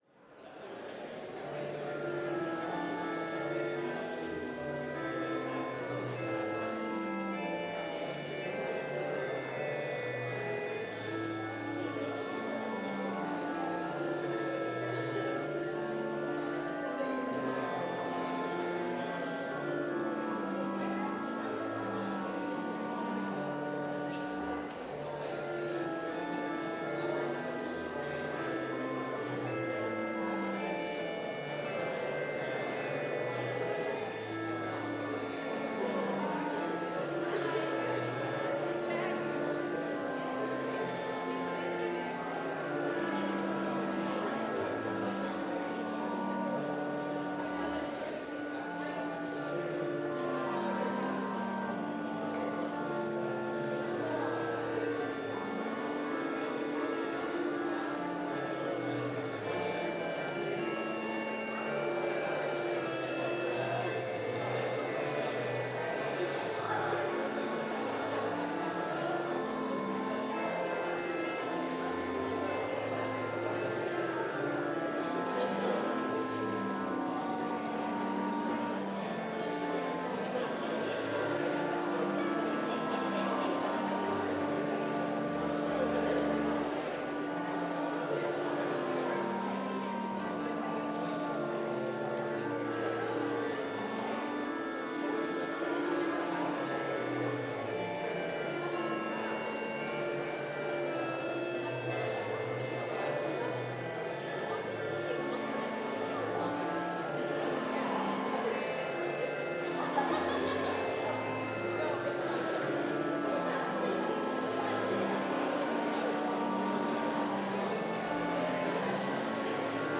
Complete service audio for Chapel - Monday, September 16, 2024